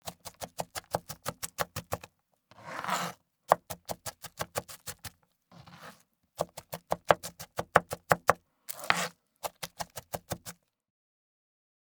Fruits and Vegetables – Onion, Cutting Small Pieces Fast
This fast-cutting kitchen sound effect will make viewers think you’re a samurai and give you credibility in making food videos.